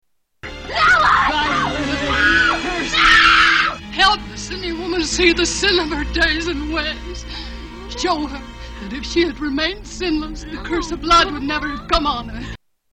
Carrie- Scream